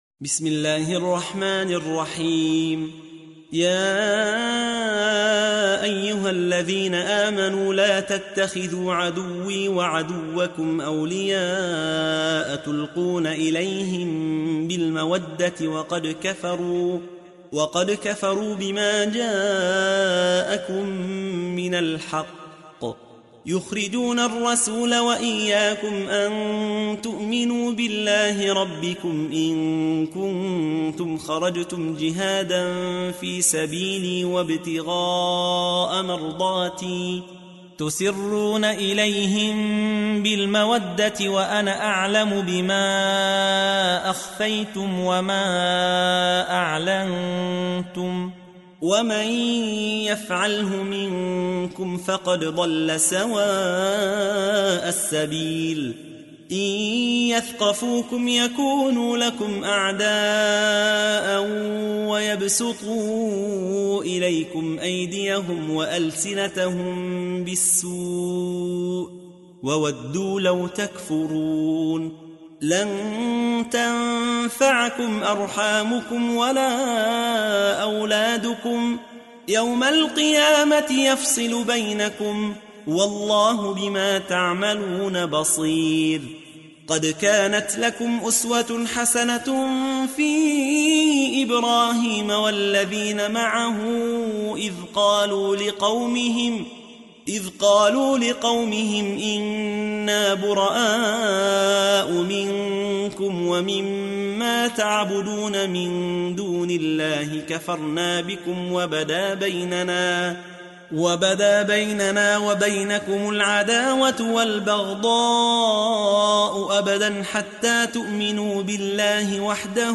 تحميل : 60. سورة الممتحنة / القارئ يحيى حوا / القرآن الكريم / موقع يا حسين